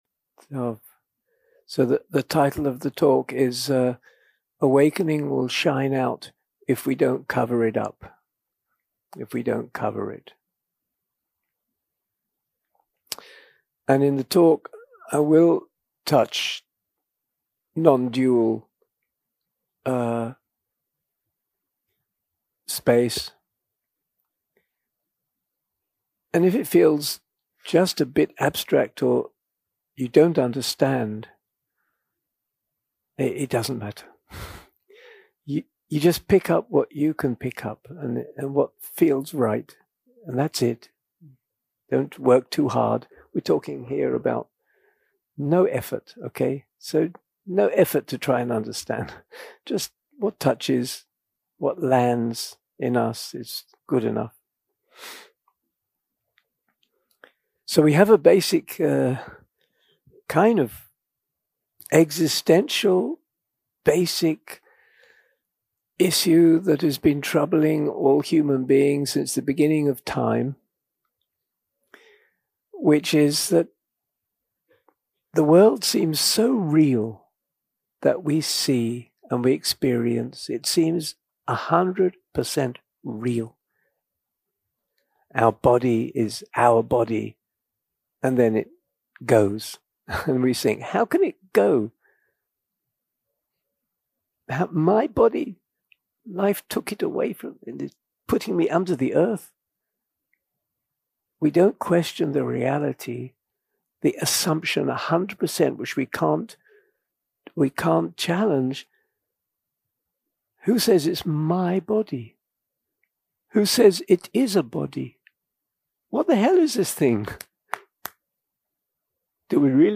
יום 2 – הקלטה 3 – ערב – שיחת דהארמה – Awakening Shines Out If We Don't Cover It
יום 2 – הקלטה 3 – ערב – שיחת דהארמה – Awakening Shines Out If We Don't Cover It 0:00 0:00 סוג ההקלטה: Dharma type: Dharma Talks שפת ההקלטה: Dharma talk language: English